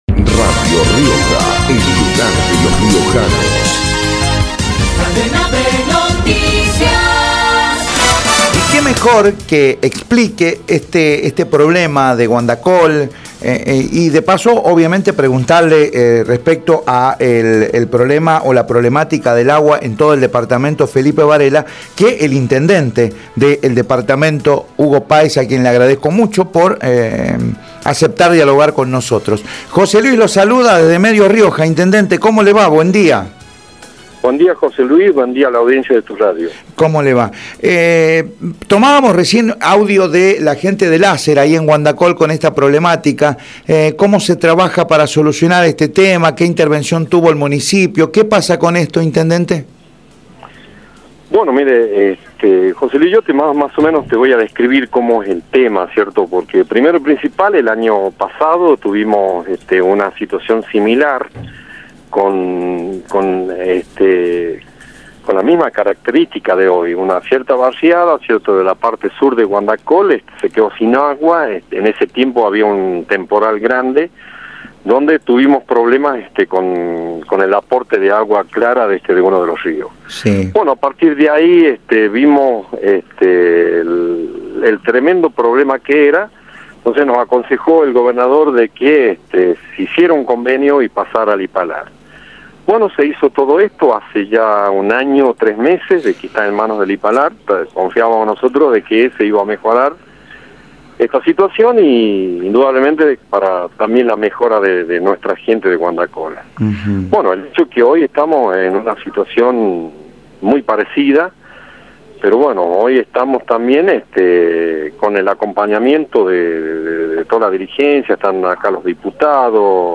Informe desde Guandacol por la falta de agua por Radio Rioja  Hugo Páez, intendente de Felipe Varela, por Radio Rioja
hugo-pc3a1ez-intendente-de-felipe-varela-por-radio-rioja.mp3